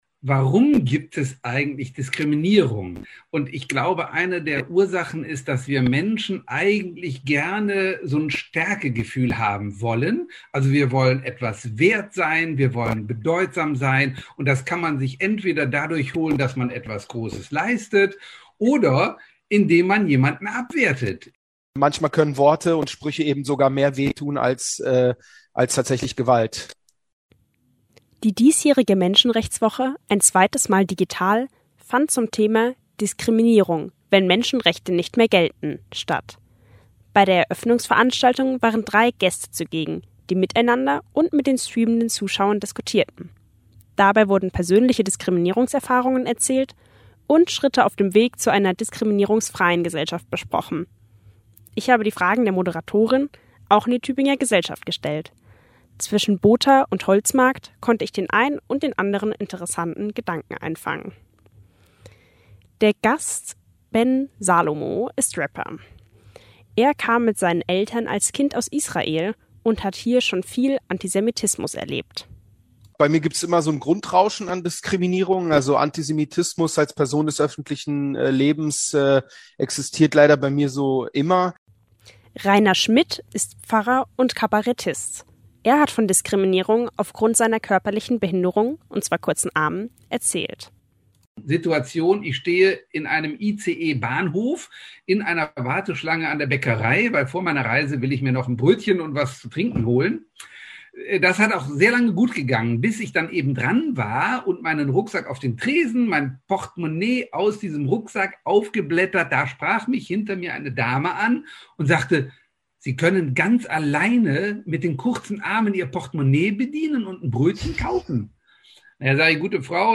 Events, Kultur, Live-Übertragungen, Regionales, Soziales
Und auch die Tübinger Passant*innen hatten einiges zu Diskriminierung im Alltag und der Arbeit gegen Vorurteile zu sagen.